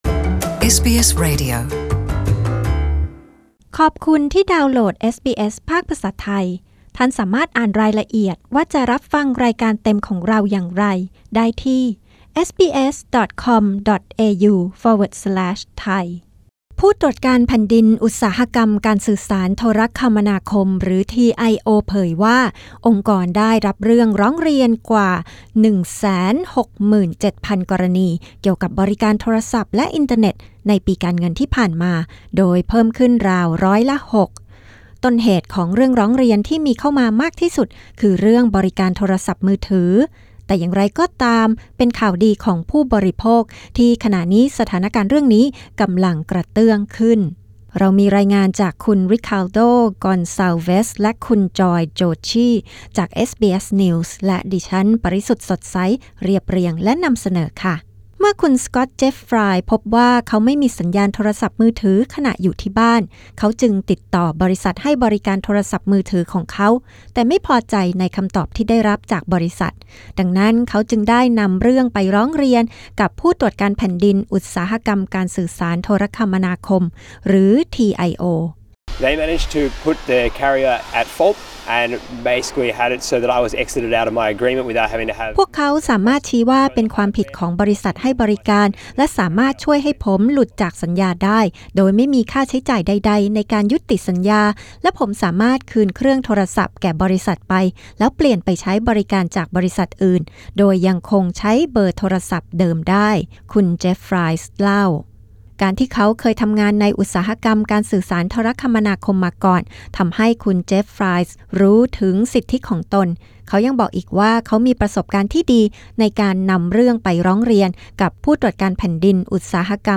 กด (►) ที่ภาพด้านบน เพื่อฟังเสียงรายงานข่าวเรื่องนี้เป็นภาษาไทย